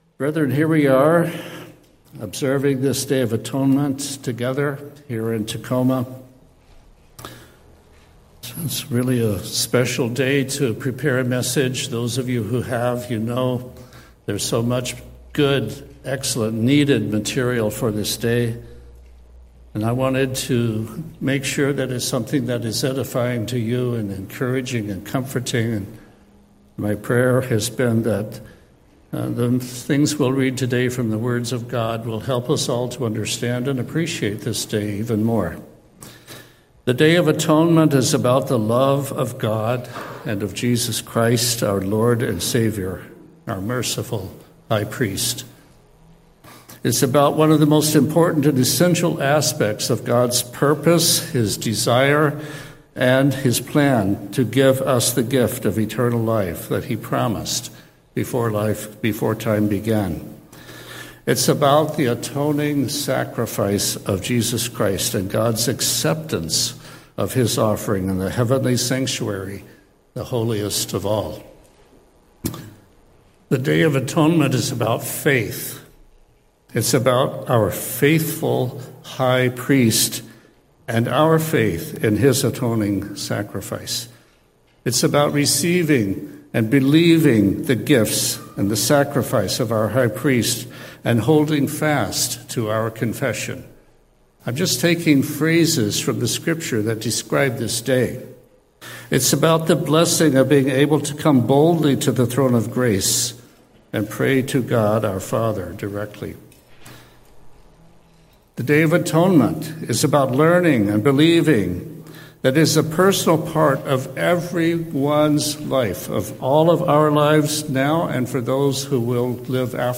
The Day of Atonement is a sacred reminder of God’s profound love and mercy, revealing the heart of His plan to reconcile humanity to Himself through Jesus Christ. This message explores how the ancient rituals of Leviticus 16 foreshadowed the perfect and eternal work of our High Priest, who entered the true heavenly sanctuary with His own blood to cover our sins once for all.